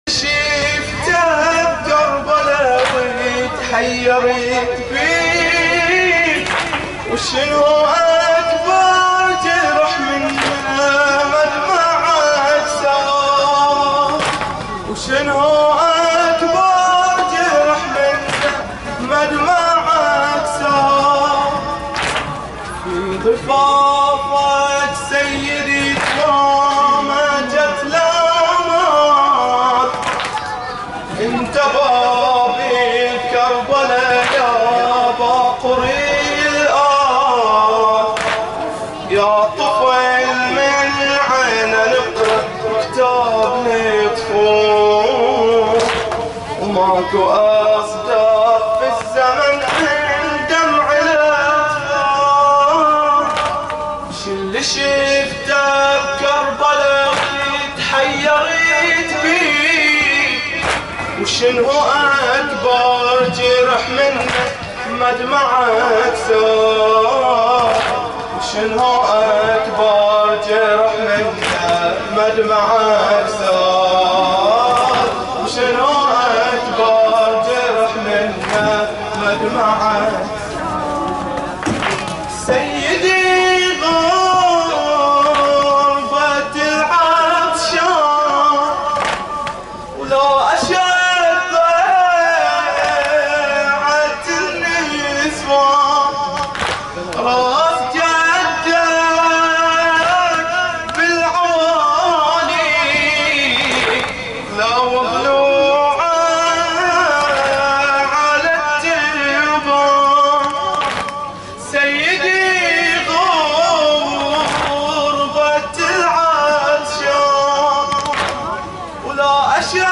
تغطية شاملة: موكب الأشبال ليلة وفاة الإمام محمد الباقر ع 1440هـ
موكب الأشبال